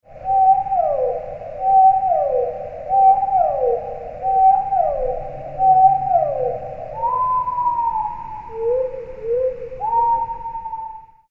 Dolphin:
Dolphins.mp3